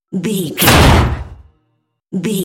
Dramatic hit metalic
Sound Effects
heavy
intense
dark
aggressive